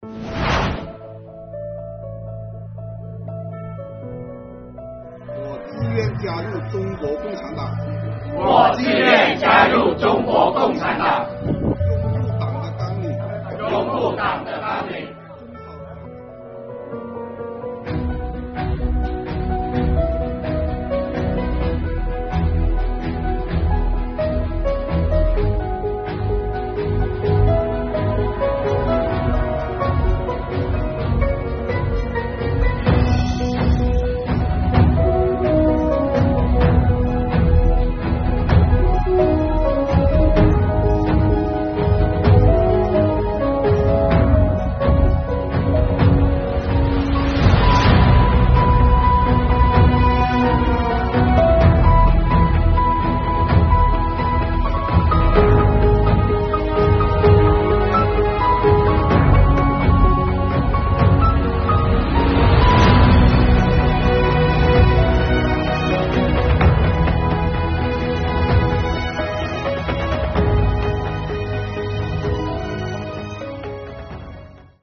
浦北县税务局红色教育现场实践活动